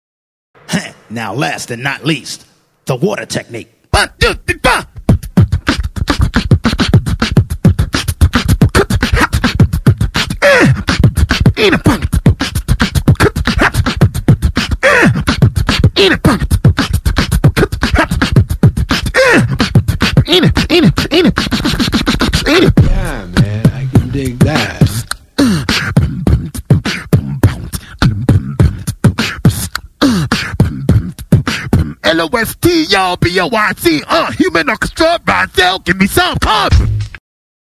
вода не до конца записана, кстати, нигде ее в полном варианте найти не могу(